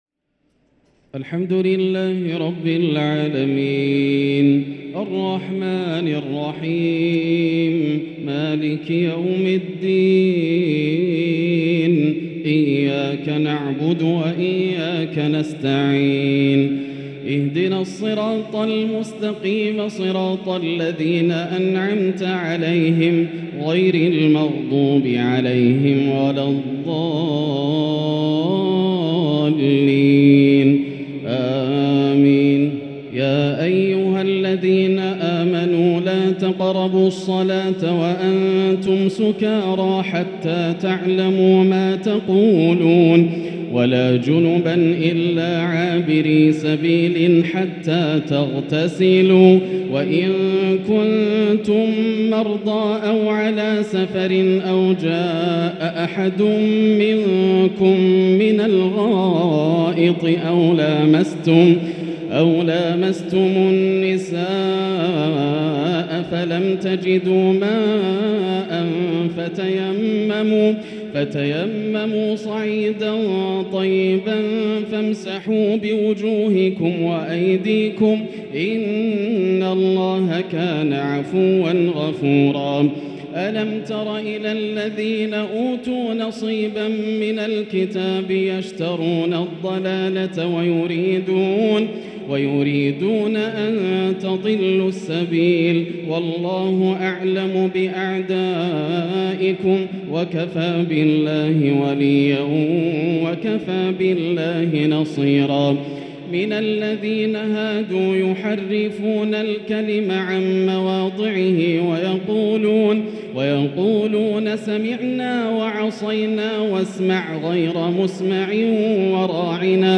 تراويح ليلة 6 رمضان 1444هـ من سورة النساء {43-87} Taraweeh 6st night Ramadan 1444H Surah An-Nisaa > تراويح الحرم المكي عام 1444 🕋 > التراويح - تلاوات الحرمين